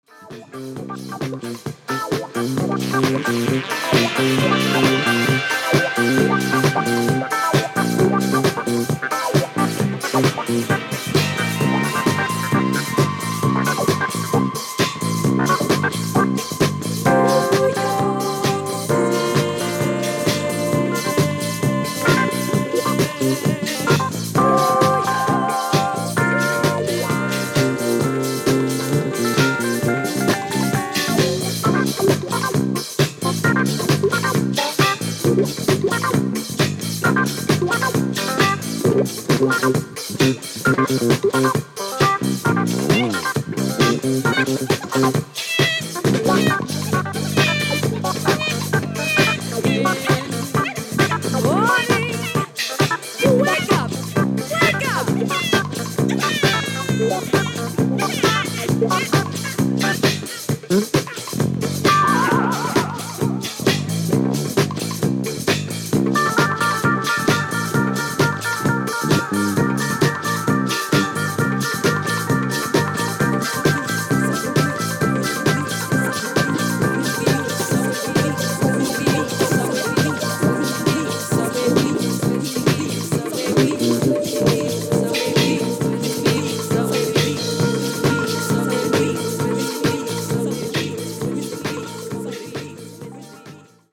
Electric Piano [Rhodes]
Percussion
Drums